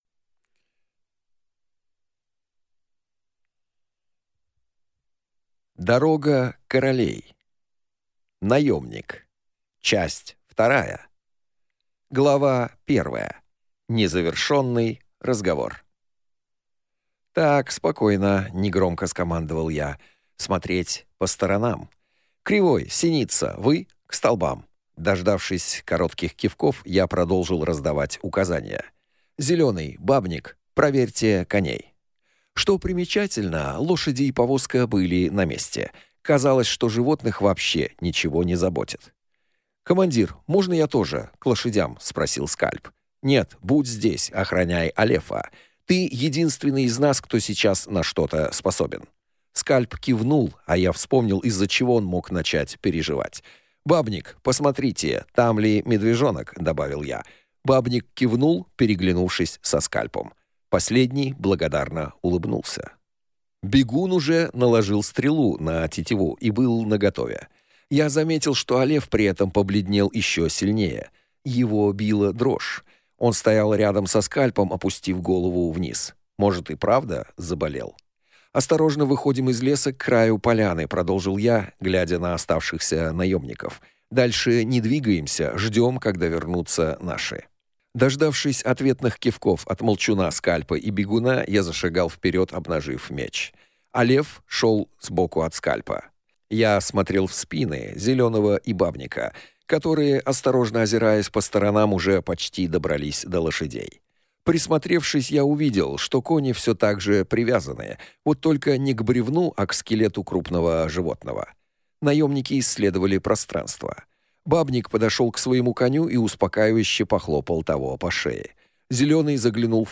Аудиокнига Дорога королей. Наемник 2 | Библиотека аудиокниг